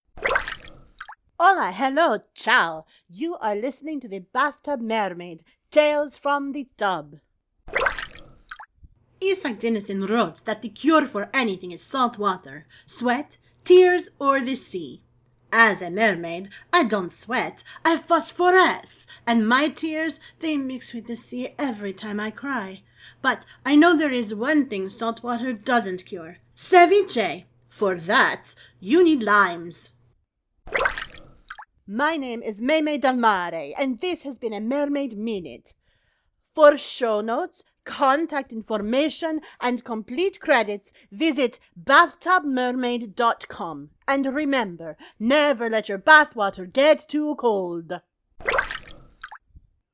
• Sound Effects are from Freesound.